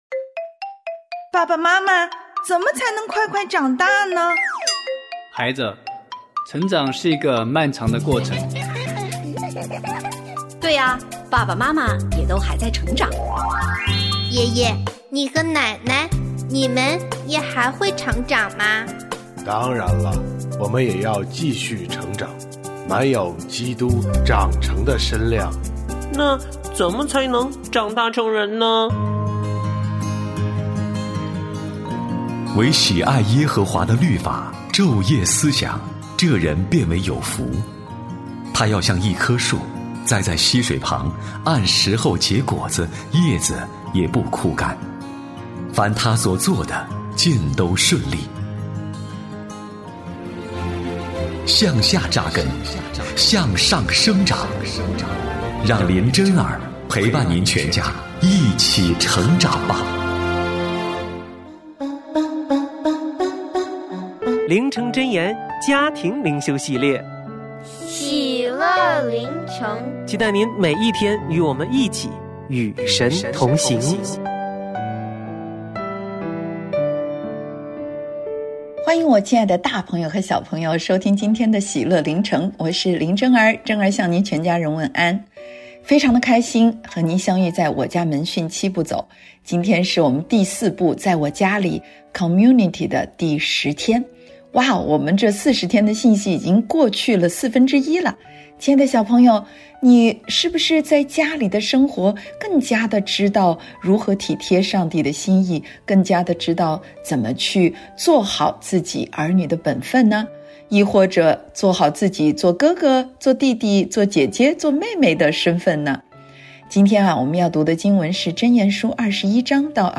婚姻私塾：理所当然的事奉，浩瀚无垠的恩典──神秘嘉宾访谈录（5）